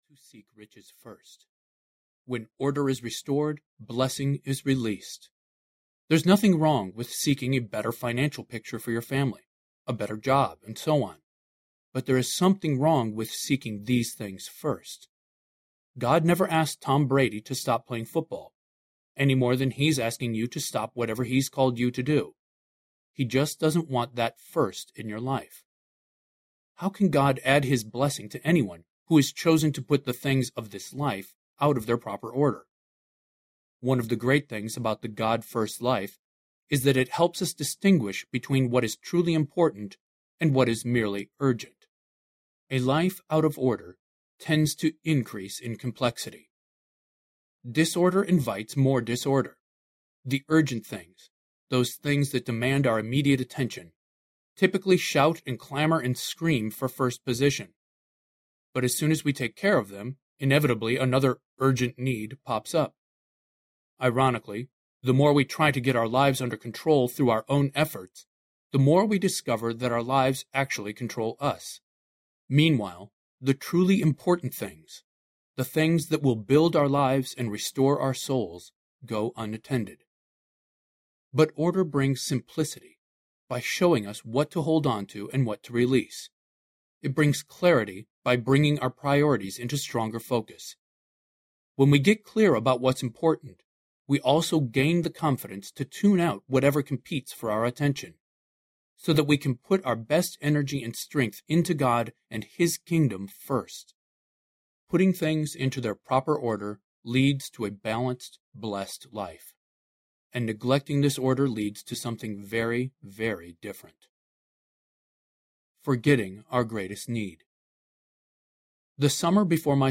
The God-First Life Audiobook
4.85 Hrs. – Unabridged